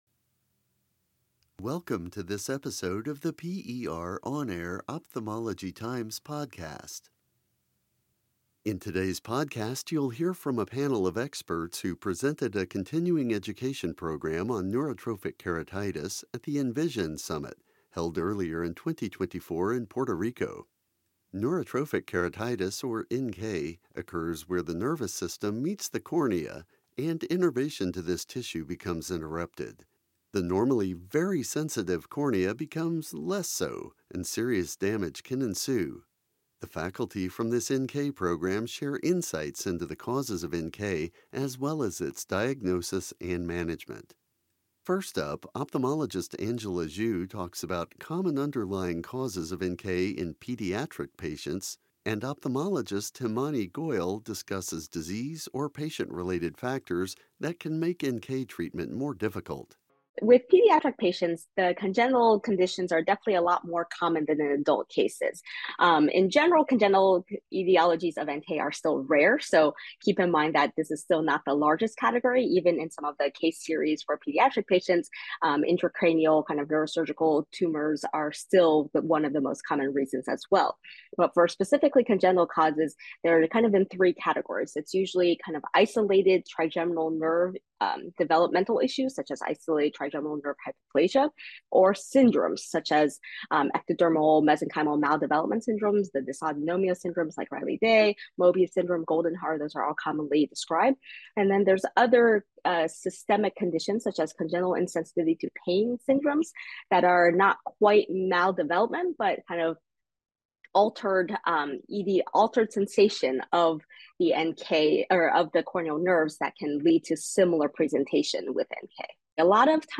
In this PER On Air Ophthalmology Times podcast, a team of experts provides insights into the diagnosis and management of patients with NK (including assessing their underlying cause of NK), with a look at the range of treatments available for patients with advanced stages of NK.